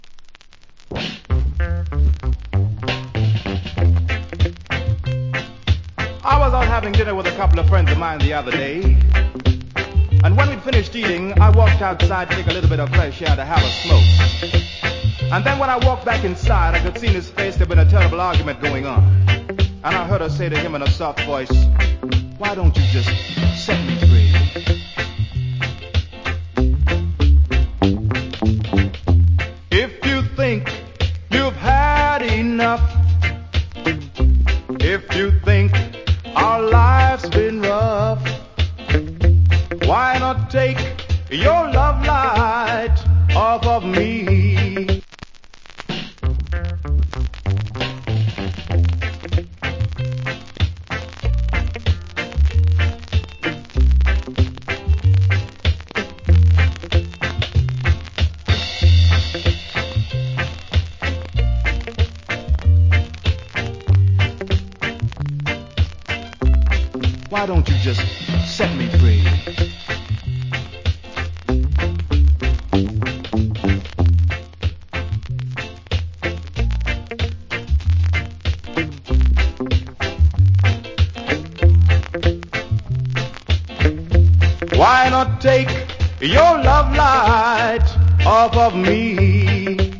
コメント Good Reggae Vocal.